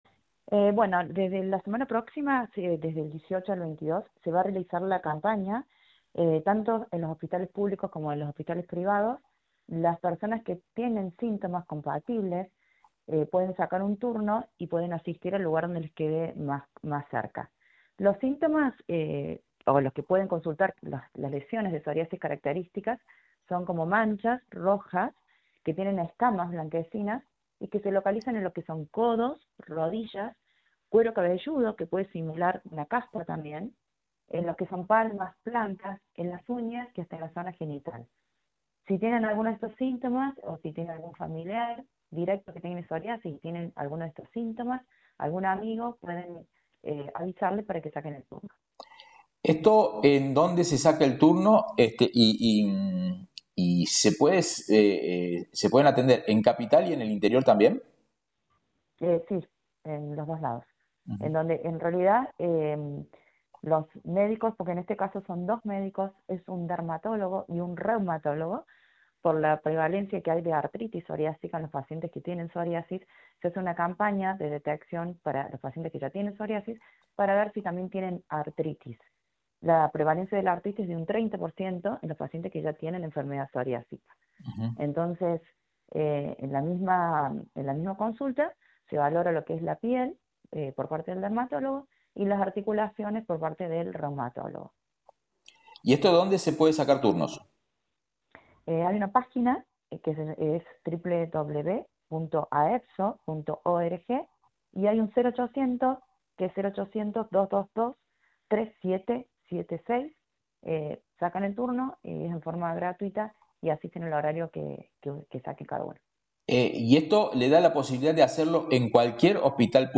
Dermatóloga